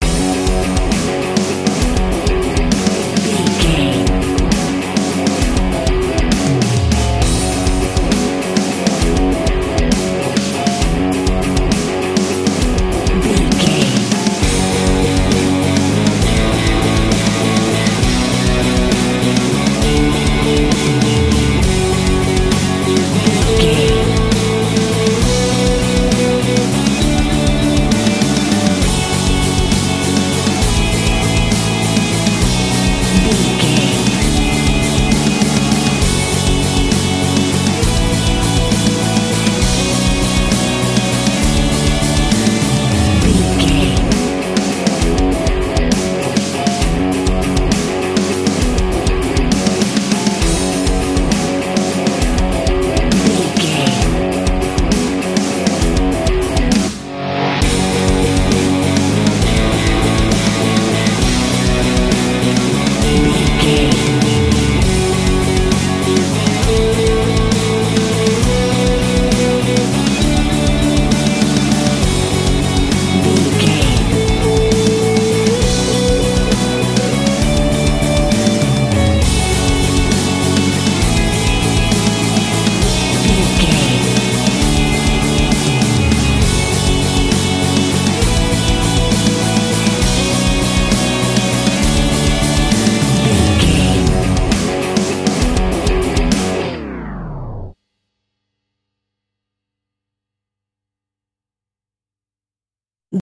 Aeolian/Minor
F#
drums
electric guitar
bass guitar
pop rock
hard rock
lead guitar
aggressive
energetic
intense
nu metal
alternative metal